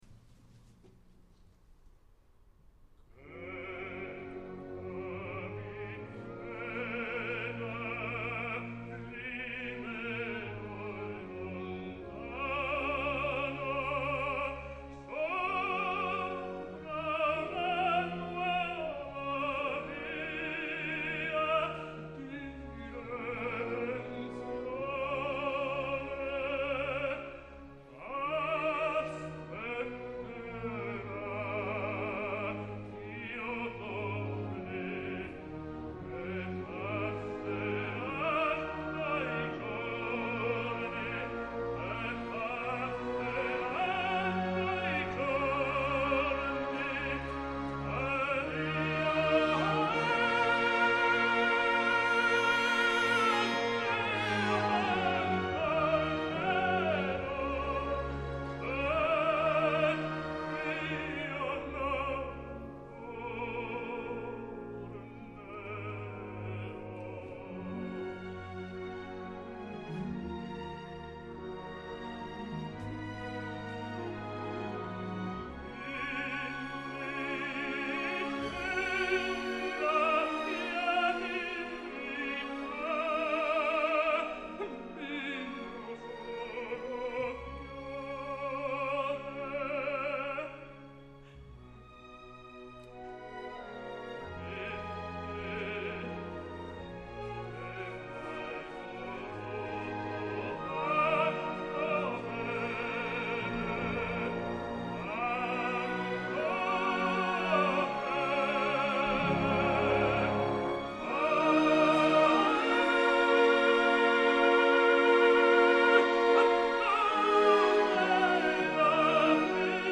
: I brani qui presentati sono tutti tratti da  registrazioni amatoriali , spesso realizzate da amici o colleghi Ne  è vietata la divulgazione con qualsiasi mezzo o utilizzo a  scopo commerciale.
Here below you can find some arias performed by  tenor  Antonello  Palombi.